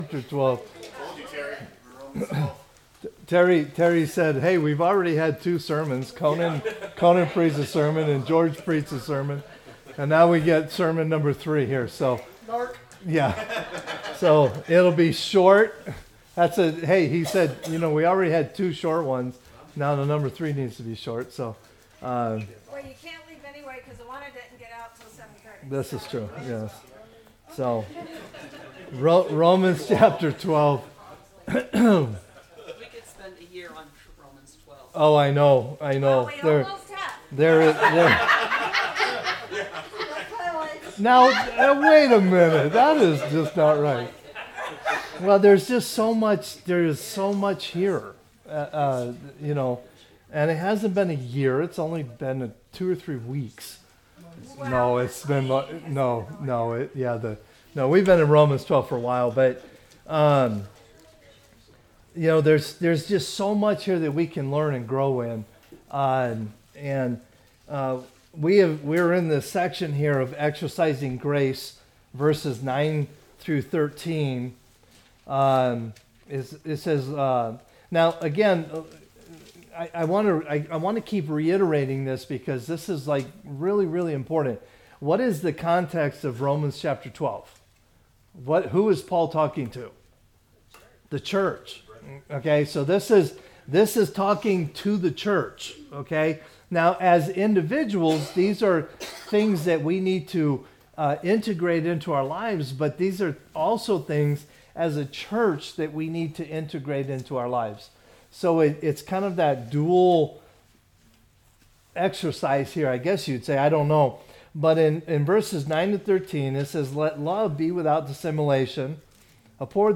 Message
Wednesday service